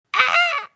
Weird Scream